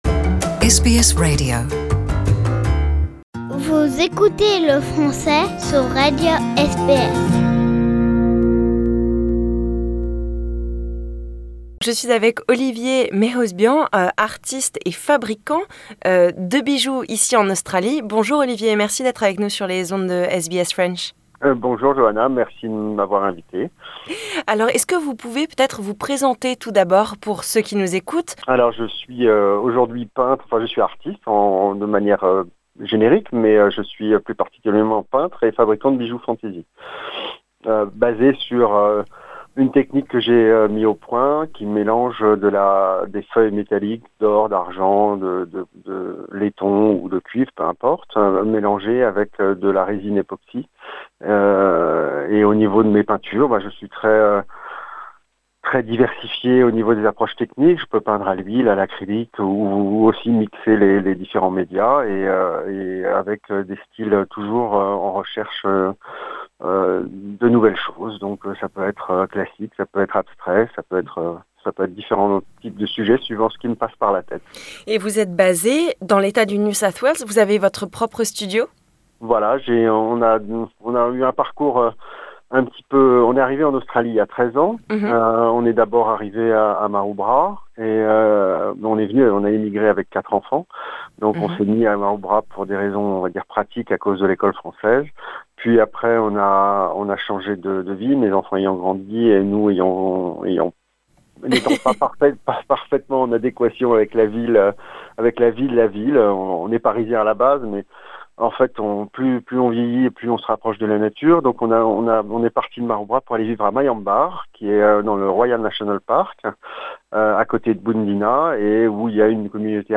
Cette interview date de novembre 2018.